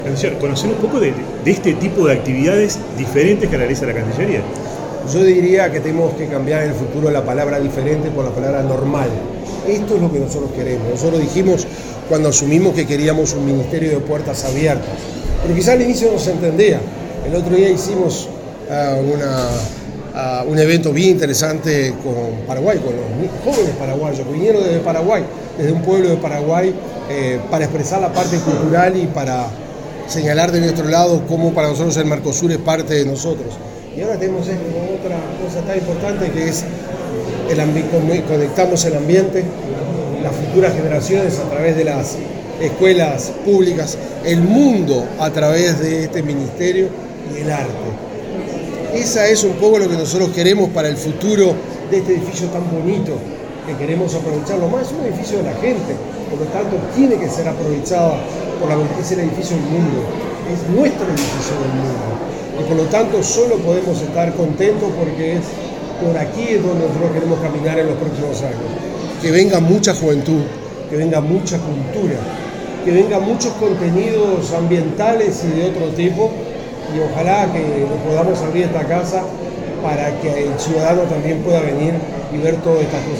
Declaraciones del canciller, Mario Lubetkin
En el marco de la exposición “Iluminando el reciclaje”, el canciller Mario Lubetkin realizo declaraciones.